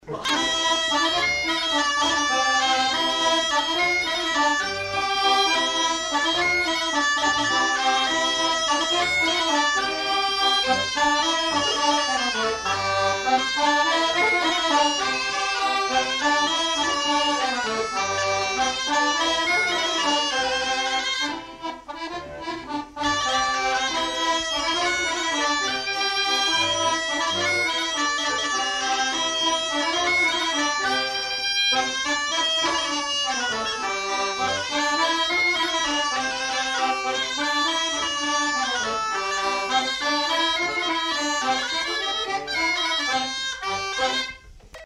Bourrée à trois temps
Lieu : Pyrénées-Atlantiques
Genre : morceau instrumental
Instrument de musique : accordéon chromatique ; cabrette
Danse : bourrée